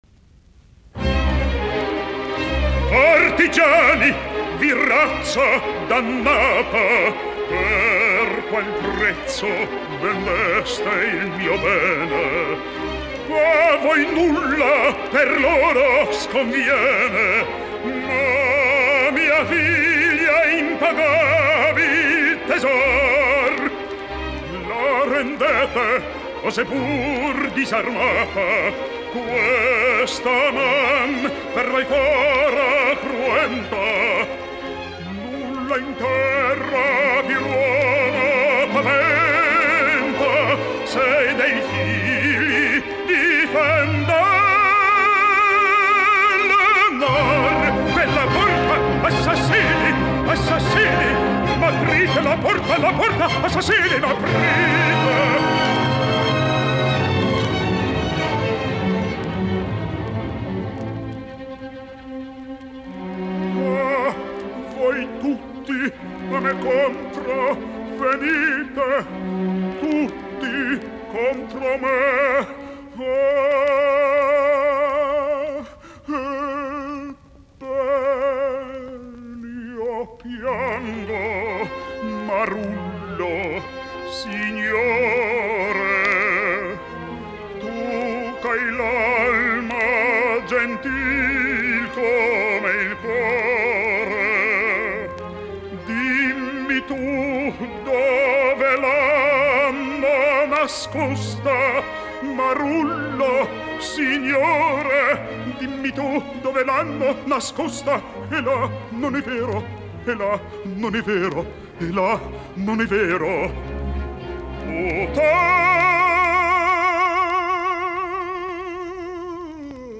musiikkiäänite
baritoni